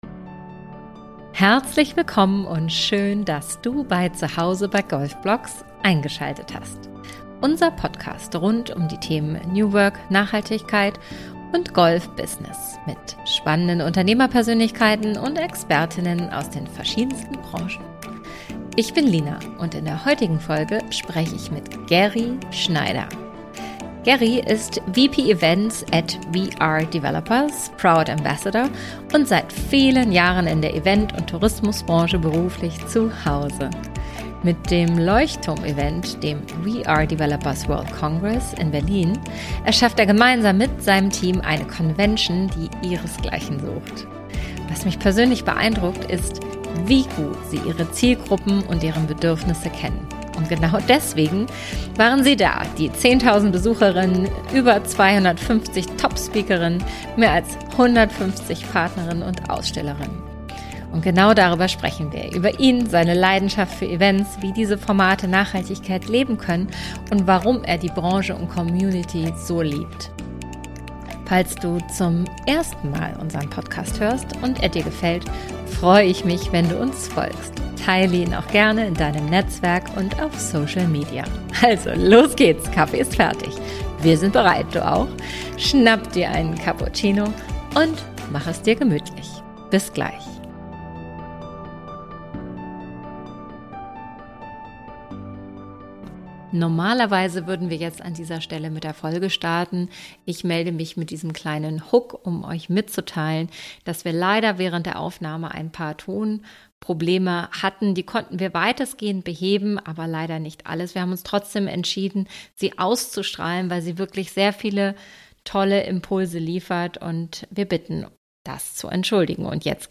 Unsere neue Podcast-Folge ist raus und es gab technische Schwierigkeiten.